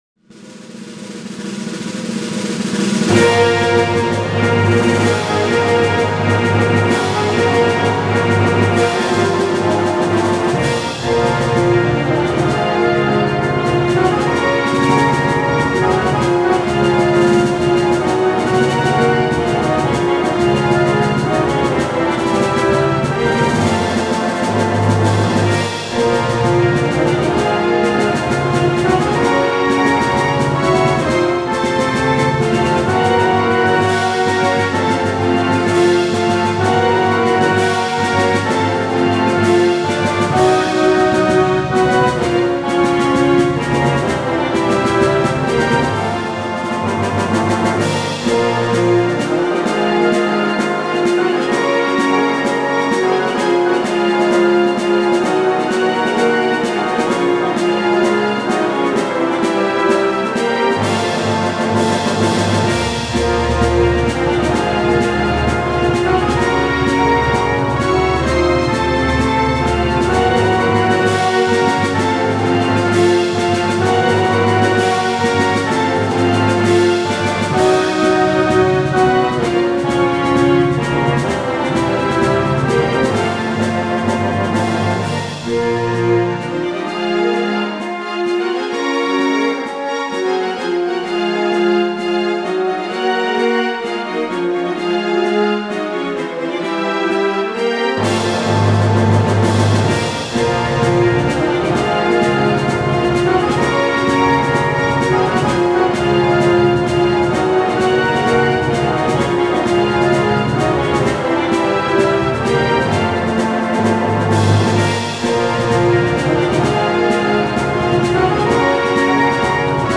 フルオーケストラVer.
完オーケストラに挑戦、是非聞いてみてください。